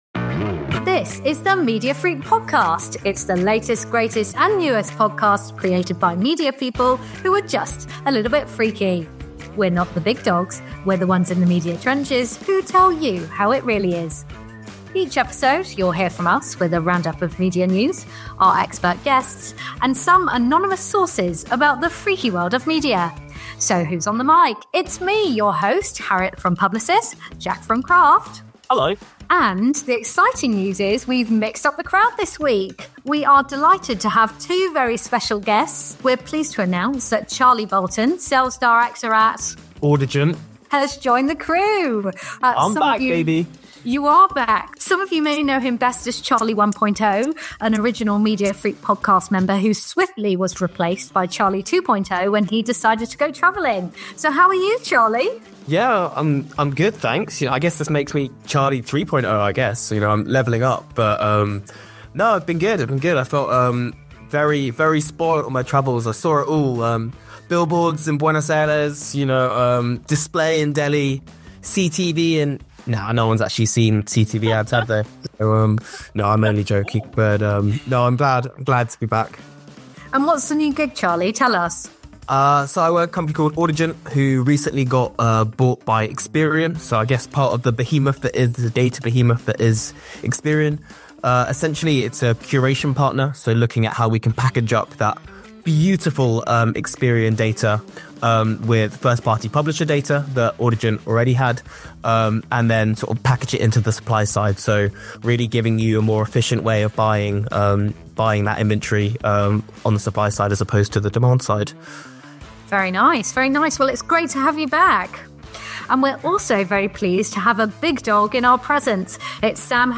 This is the marketing and advertising podcast presented by a team from across the industry. Each episode will feature news and discussion from across our business.